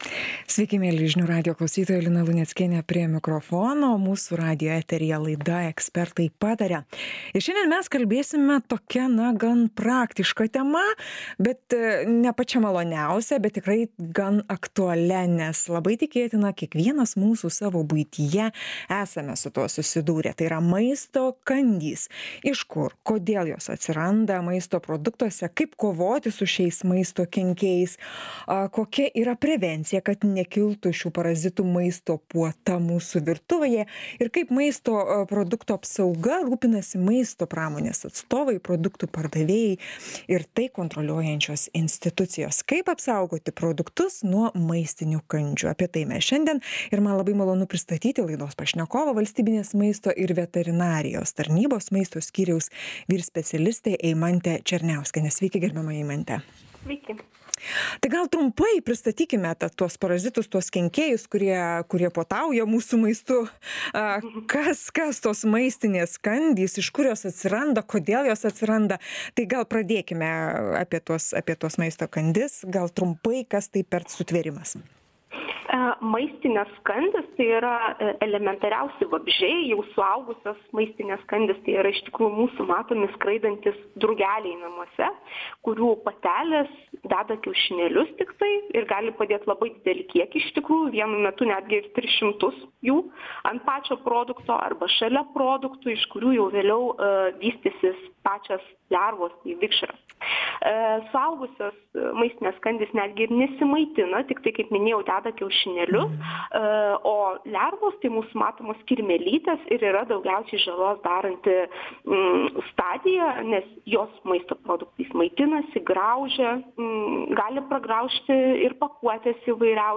Tad, ką daryti, kad nepultų maisto kandys? Pokalbis